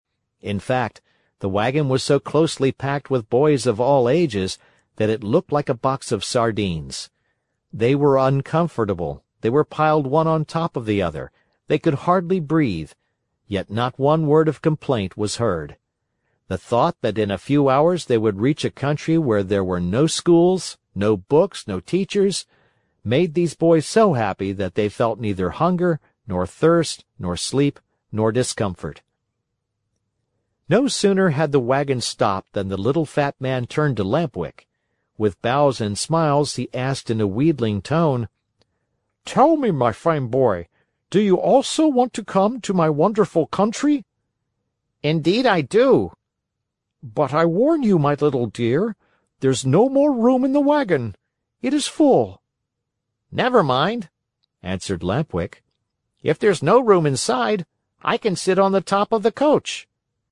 在线英语听力室木偶奇遇记( 第111期:在玩具国享乐(2)的听力文件下载,《木偶奇遇记》是双语童话故事的有声读物，包含中英字幕以及英语听力MP3,是听故事学英语的极好素材。